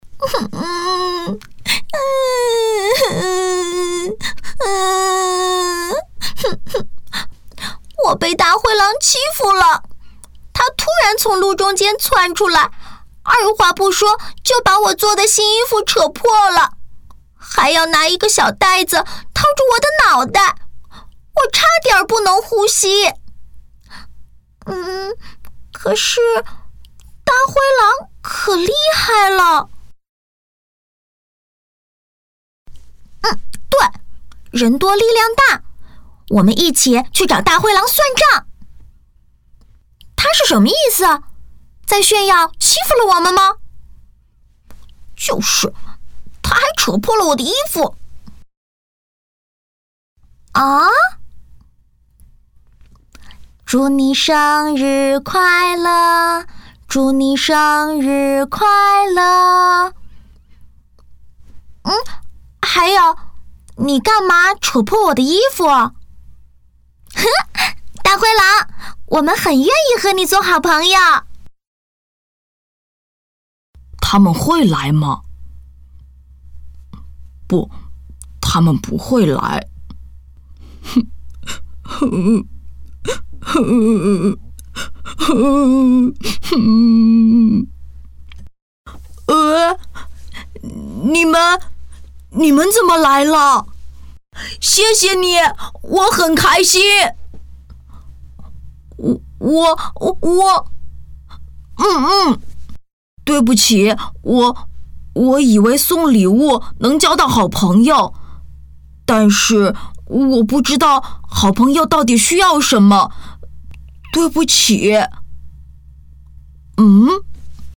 专注高端配音，拒绝ai合成声音，高端真人配音认准传音配音
女10